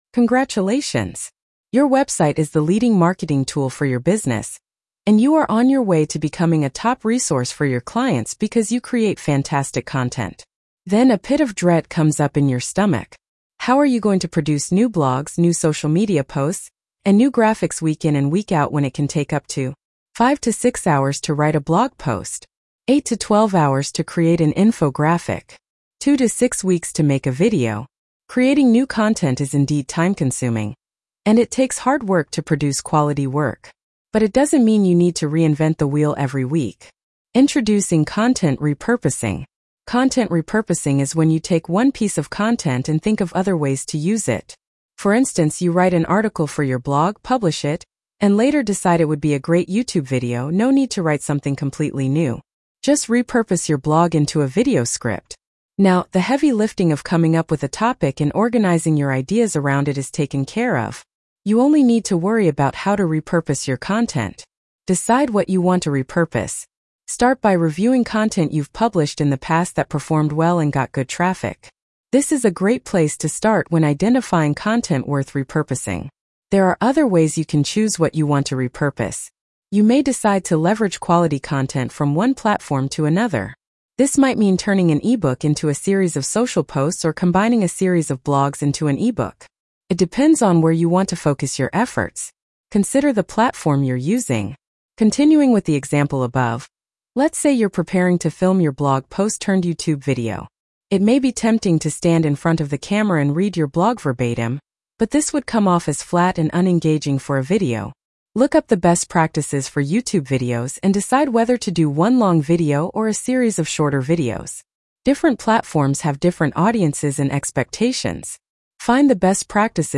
The Short and Sweet of Repurposing Content Blog Narration.mp3